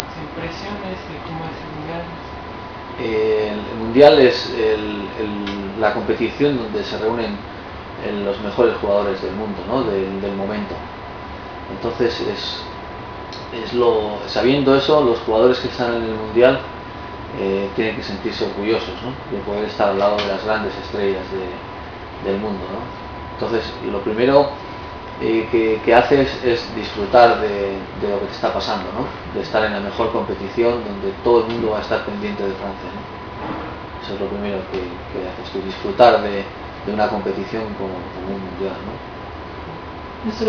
ワールドカップ出場経験者であるベギリスタイン選手にワールドカップについて、そして浦和レッズから代表として出場する３人の選手についてのコメントを語ってもらいました。